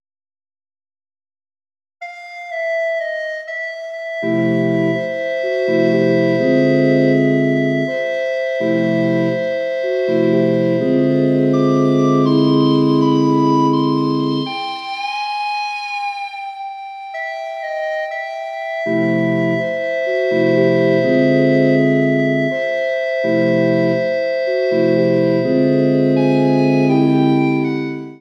S, S, A, A, T, T, B, B(or GB),GB (or FcB) Tubular Oscillations is a piece for recorder orchestra characterized by various thematic waveforms, one of which is a half-step wave.
For special effect, pitch bending by overblowing the recorder to raise the pitch is featured on various lines.